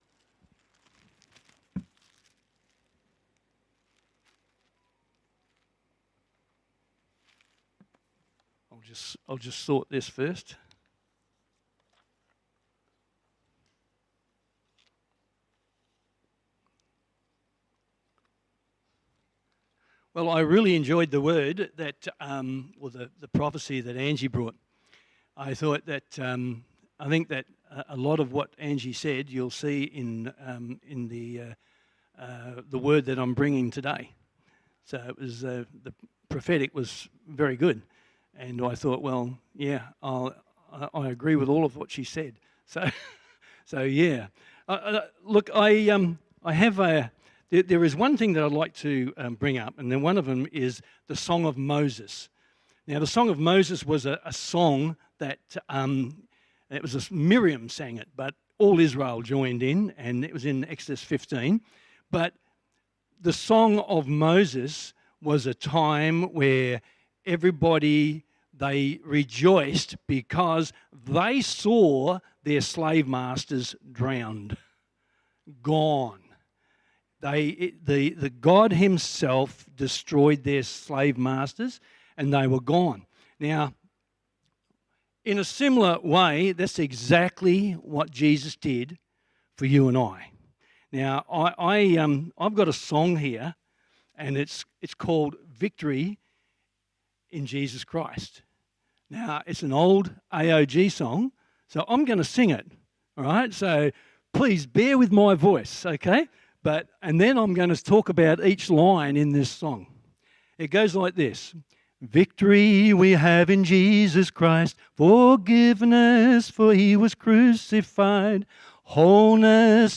Guest Sermon